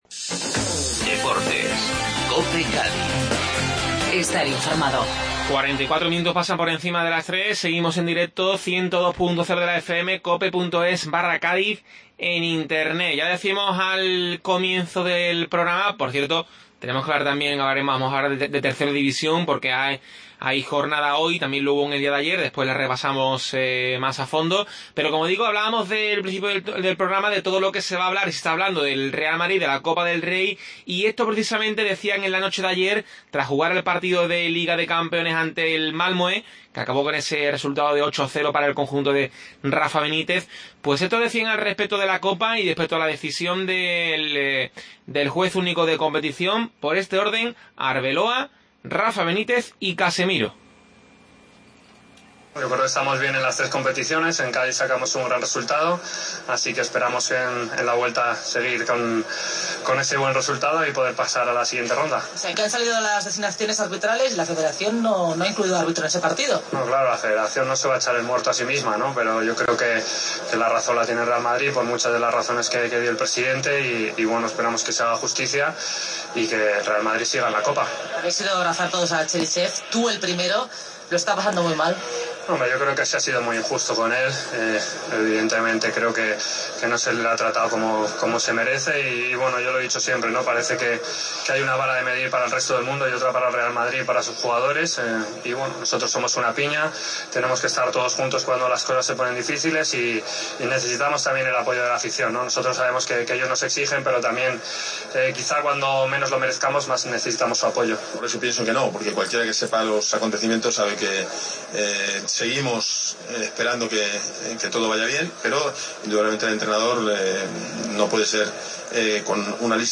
AUDIO: Escuchamos a Rafa Benítez, Arbeloa y Casemiro. Repasamos la jornada de 3ª División y analizamos la derrota del San Fernando